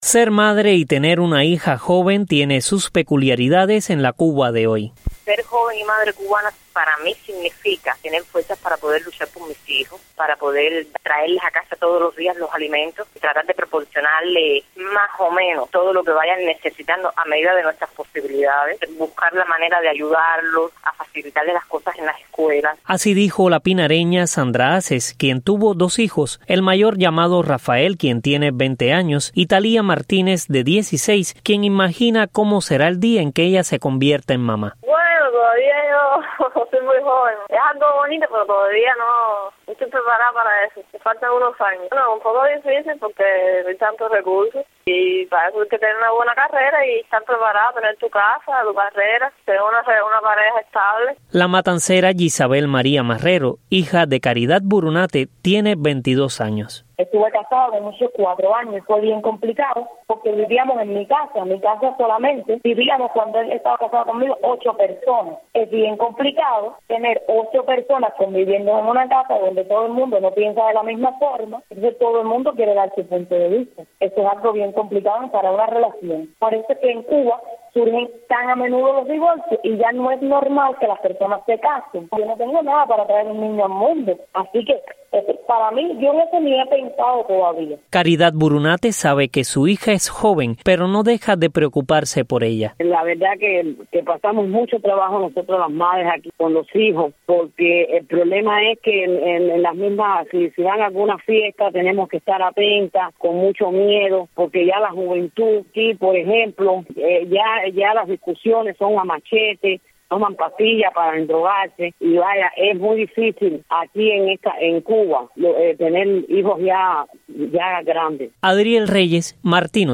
entrevistó a dos madres cubanas y a sus hijas para que hablen de sus principales retos y desafíos.